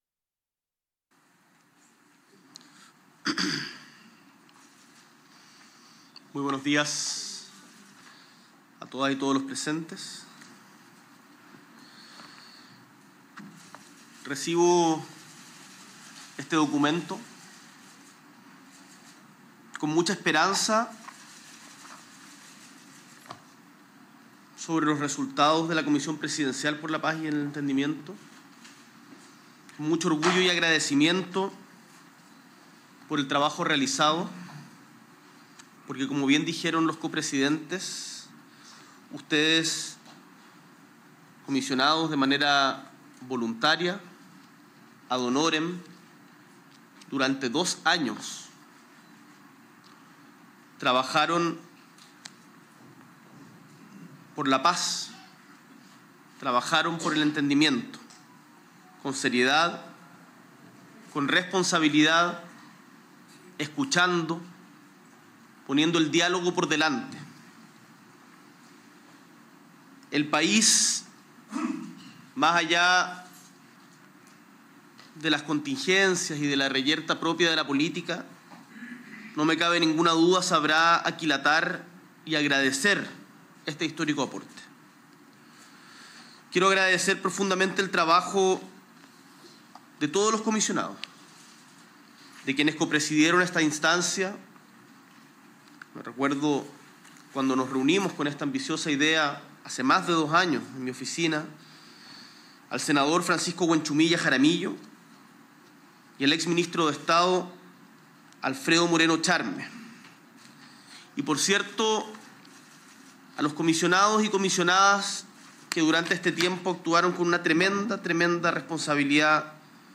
S.E. el Presidente de la República, Gabriel Boric Font, recibe el informe final de la Comisión Presidencial para la Paz y el Entendimiento junto a las ministras y ministros del Interior, Álvaro Elizalde; Hacienda, Mario Marcel; Secretaria General de la Presidencia, Macarena Lobos; Desarrollo Social y Familia, Javiera Toro; y Agricultura, Esteban Valenzuela.